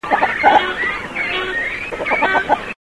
głosy